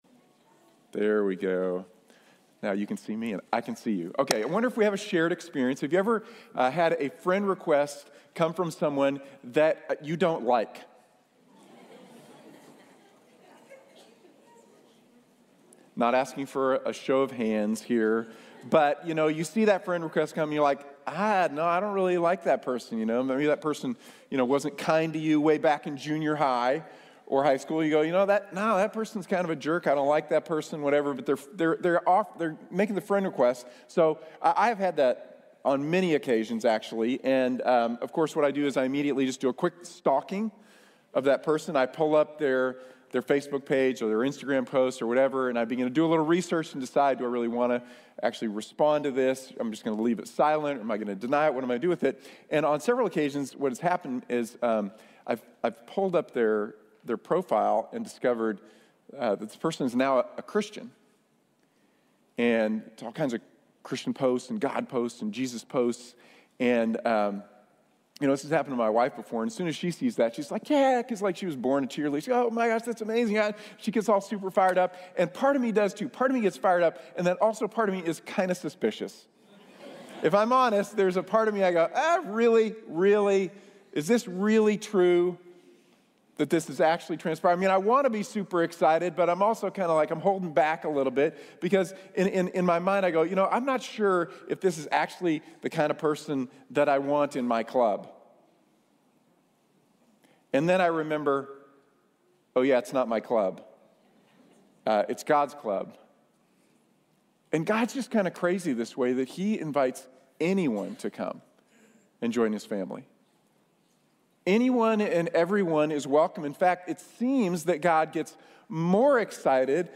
Theology Proper – God, Our Father | Sermon | Grace Bible Church